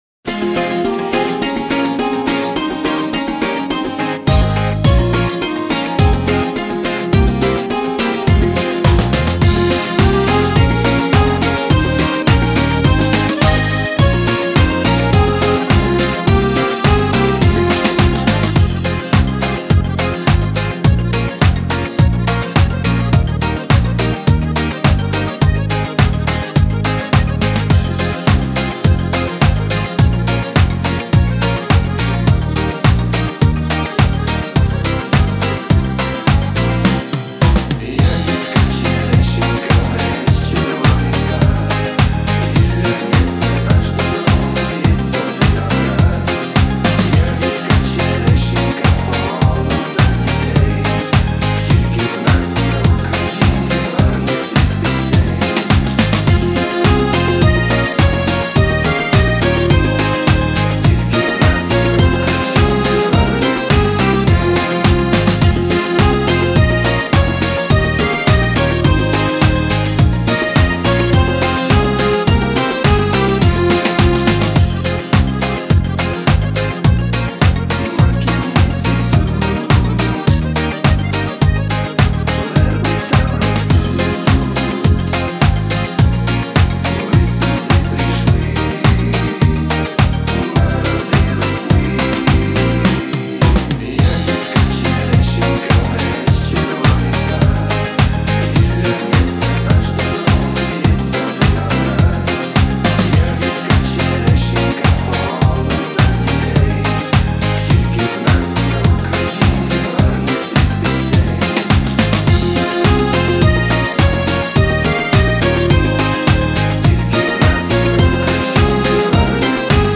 Украинские